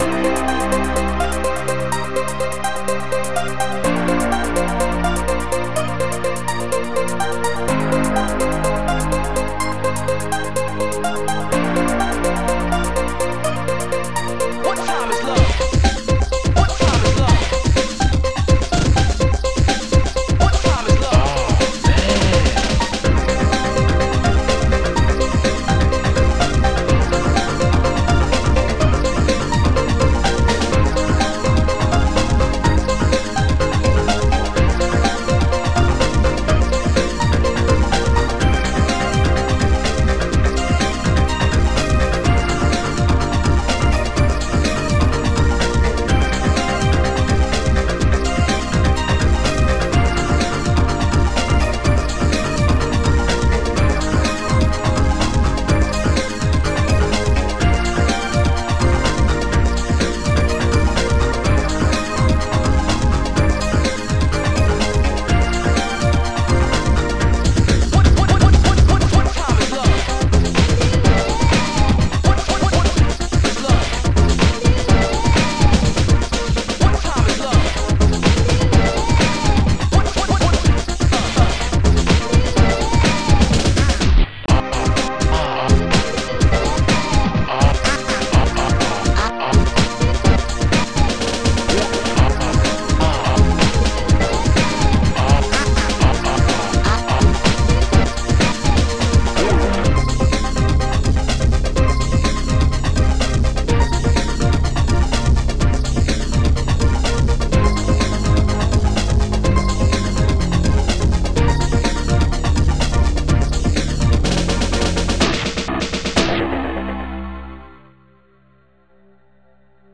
Protracker Module  |  1988-10-28  |  215KB  |  2 channels  |  44,100 sample rate  |  2 minutes, 2 seconds
Protracker and family
plague-harp
ST-01:FUNKBASS
HiHat2